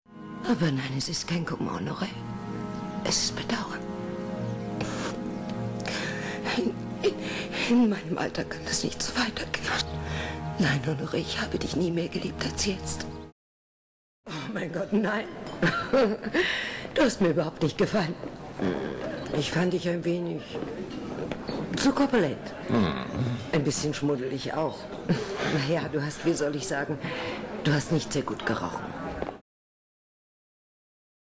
Synchronschauspielerin
Hier ein paar SPRACHPROBEN